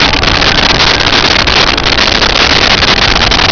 Sfx Pod Chop B Combo Loop
sfx_pod_chop_b_combo_loop.wav